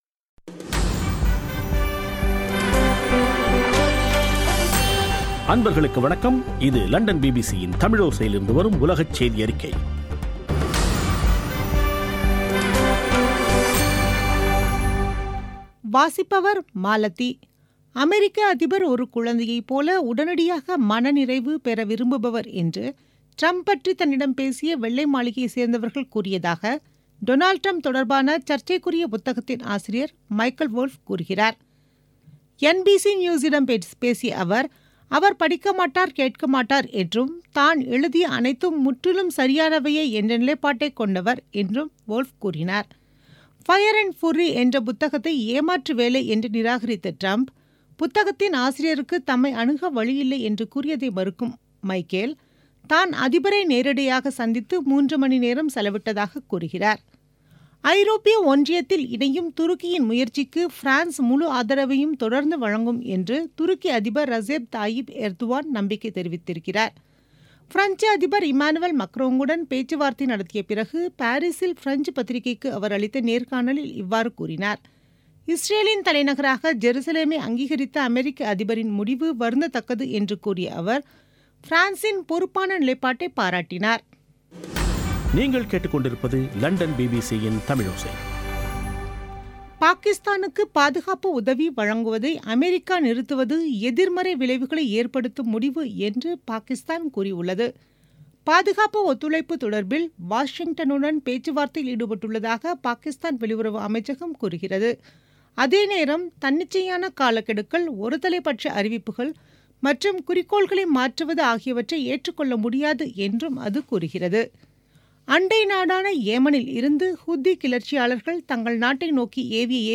பிபிசி தமிழோசை செய்தியறிக்கை (05/01/2018)